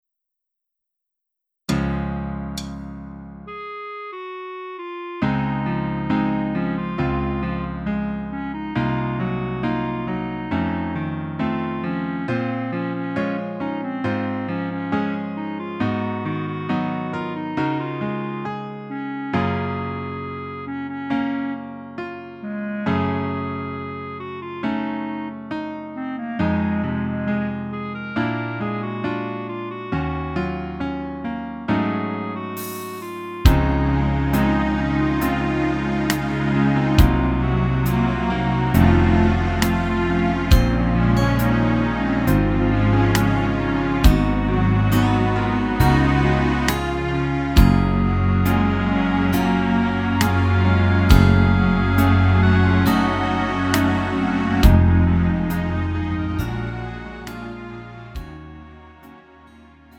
음정 원키 4:01
장르 가요 구분